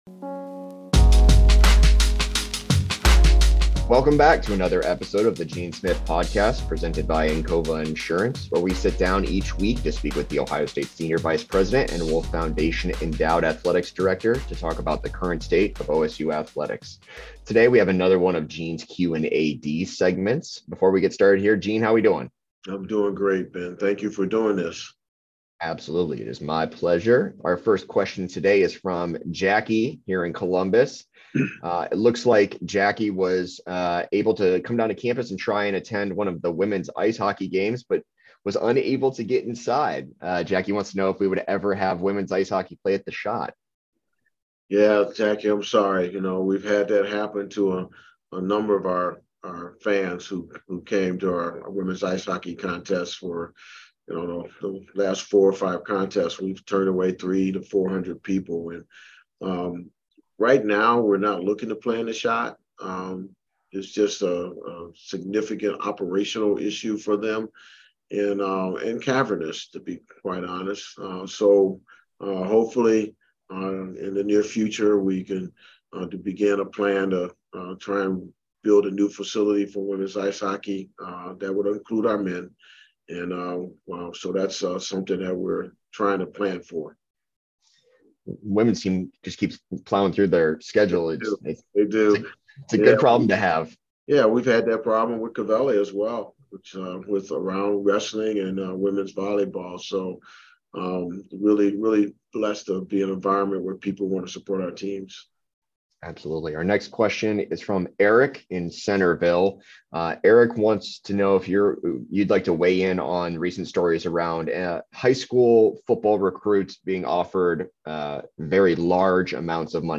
In his Q&AD segment, Gene Smith fields listener questions about reaching capacity at recent women's ice hockey games, what it means having James Laurinaitis back at the Woody Hayes Athletic Center, the Spring Game's impact, and more.